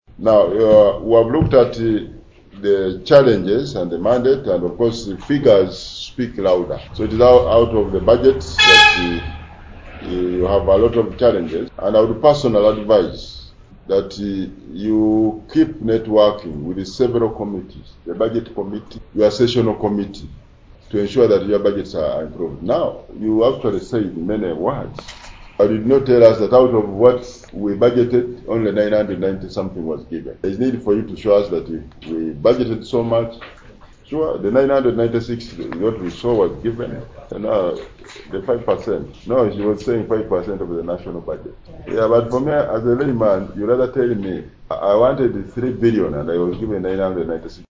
James Kubeketeria, the head of the Appointment Committee and MP for Bunya East, assured the UHRC that their concerns had not fallen on deaf ears. He expressed the committee's commitment to addressing the issue of inadequate funding, acknowledging the critical role the UHRC plays in promoting human rights and justice in Uganda.